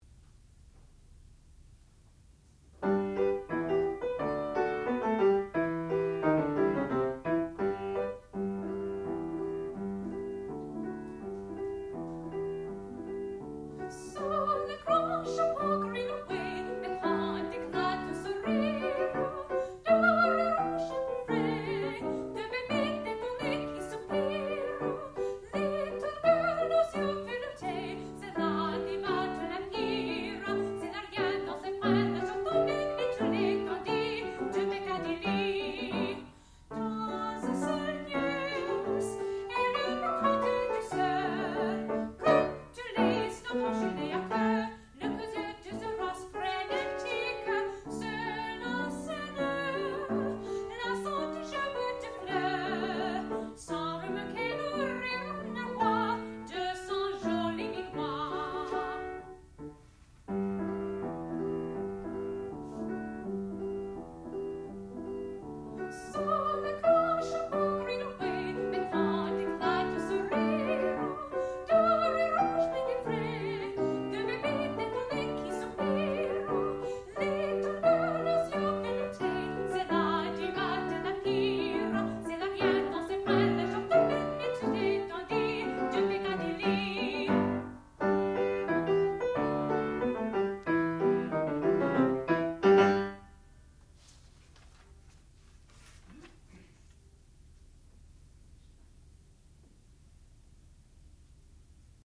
classical music
piano
saxophone
soprano